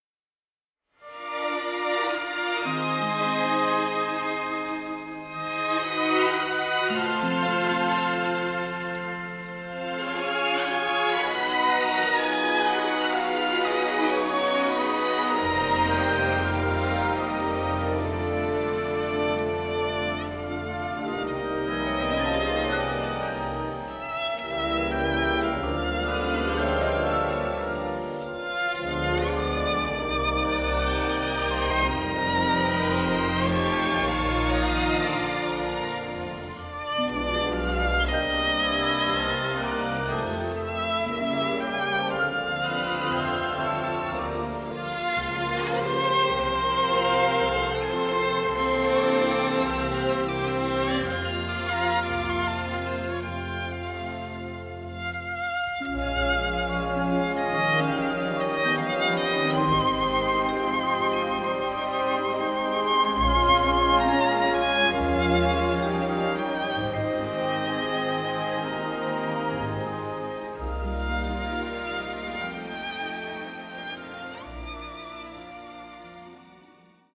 Violin Solo and Orchestra